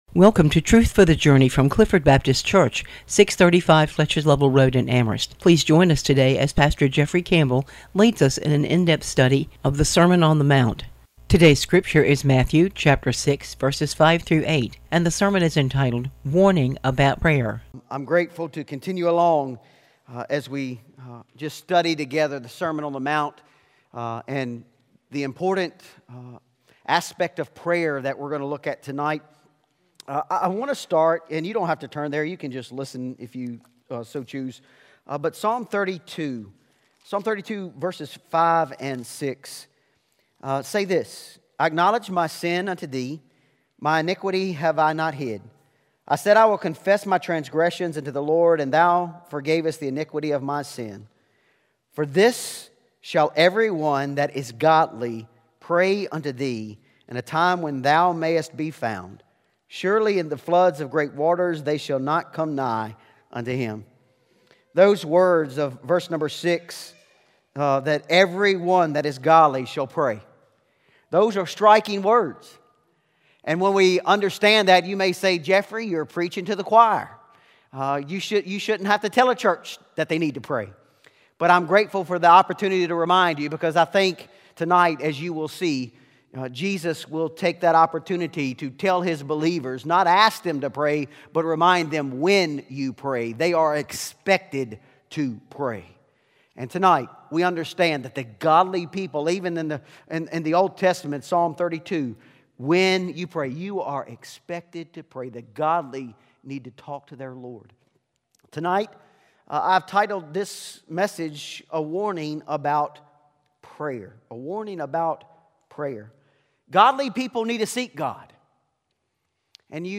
Sermon on the Mount: "Two Options, One Way", Matthew 7:13-14